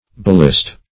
Search Result for " baalist" : The Collaborative International Dictionary of English v.0.48: Baalist \Ba"al*ist\, Baalite \Ba"al*ite\, n. A worshiper of Baal; a devotee of any false religion; an idolater.